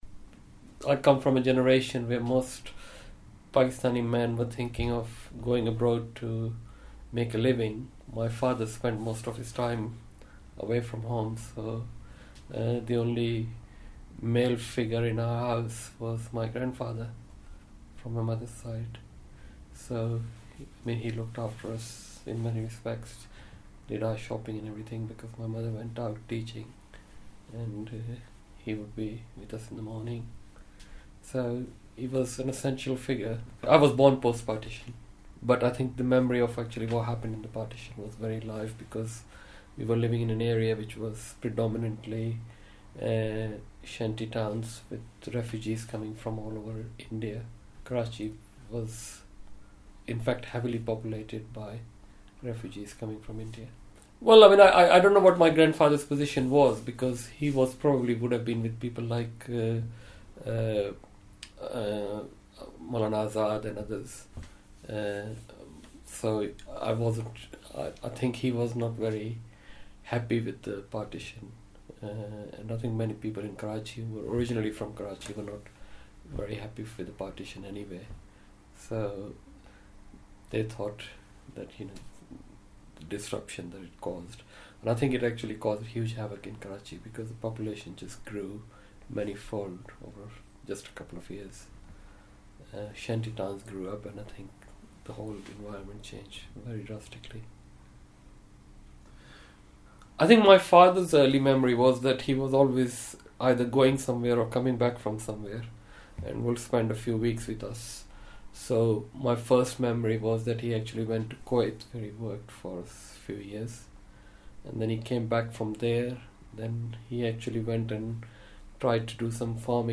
Edit of interview